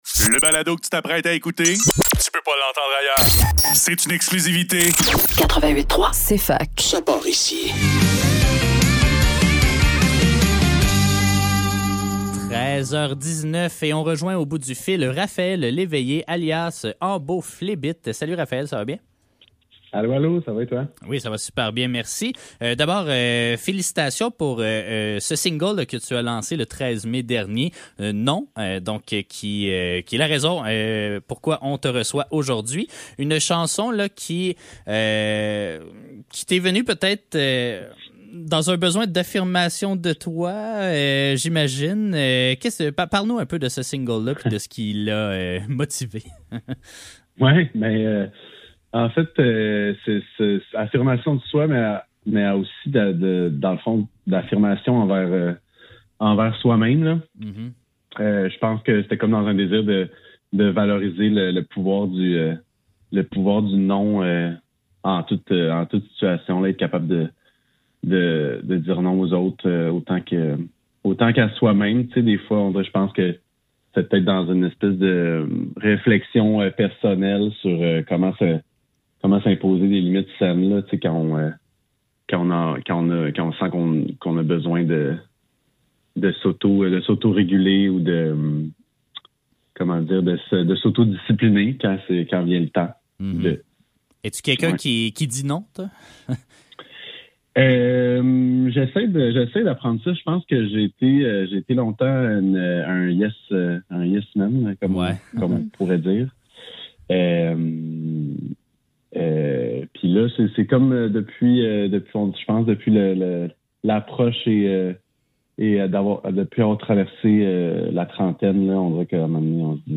Cfaktuel - Entrevue : Embo/Phlébite - 29 Mai 2025